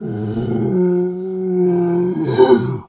bear_roar_07.wav